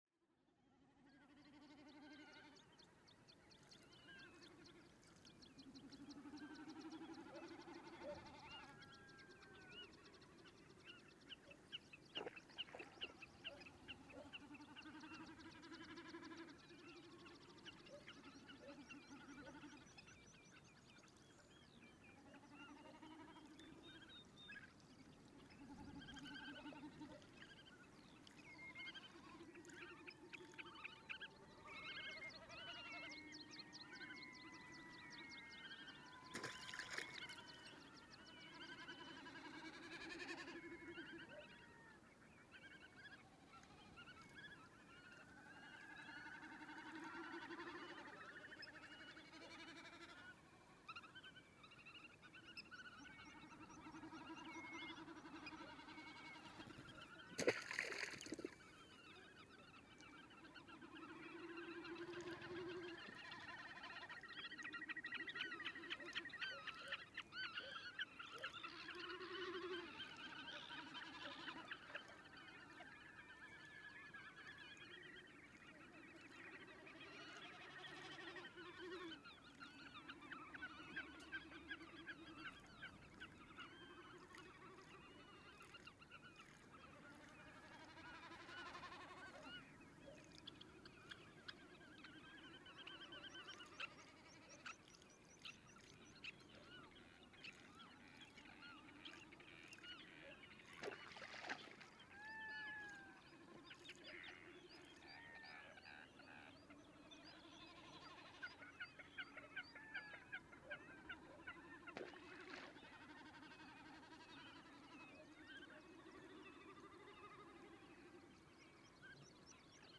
Posted in Náttúra, tagged Birds, Friðland í Flóa, Friðland í Flóa 2012, Fuglar, Nature reserve, Rode NT1a, Sound Devices 744 on 1.9.2012| 13 Comments »
Now the microphones are in the wetland, about 300 meters from the shelter . This is close to a pond, early morning 25th of June, around 3am. Now and then the gust strokes the field, but later one it gets more quiet.
Birds pass by with wing flaps. There is also a lonely barking dog, bleating sheep and whinny horses somewhere far away. When morning sunbeams warm the field and the wind goes calm, billions of gnats start to fly in thick clouds up and down with impressive noise.
This is a very quiet nature recording so you should not play it loud.
Best way to listen details and explore all bird species is using quality open headphones This recording contain also very loud session when all Loons in all ponds in the area “scream” a territory call.